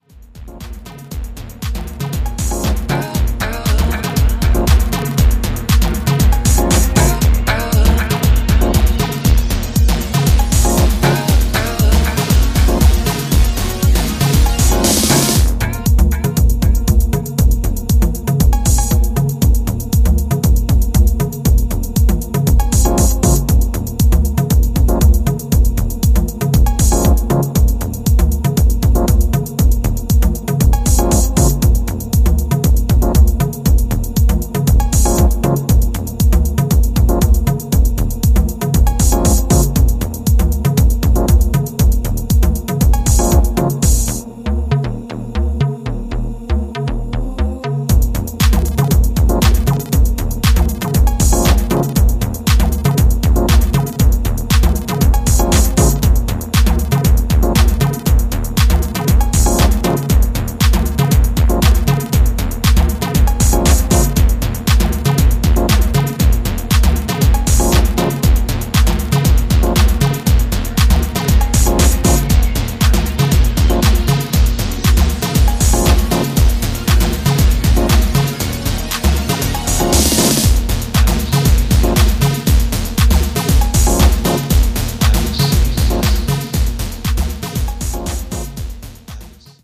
本作でも繊細でムードあるディープハウスサウンドをますます確立しています。
よりタフなドラムとベースで都市の深夜を感じる鼓動感が見事に表現されています。